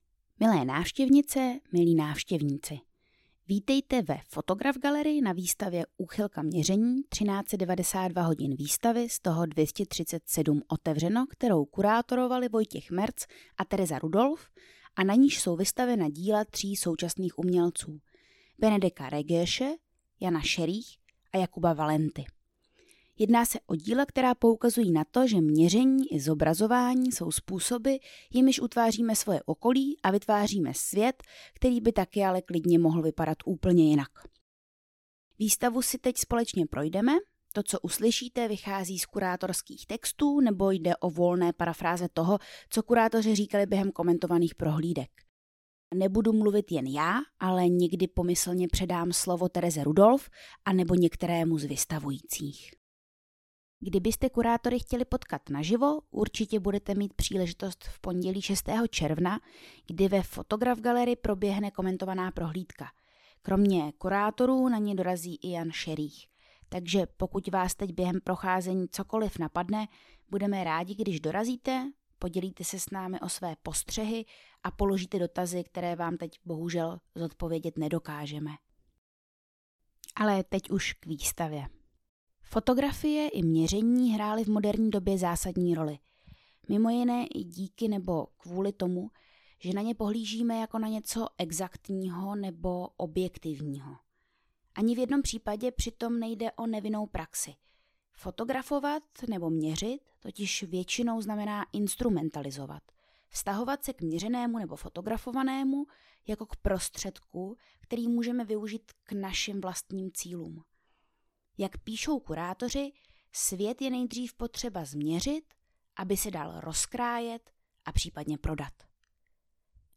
uchylka-pruvodce_audio-komentovana-prohlidka.mp3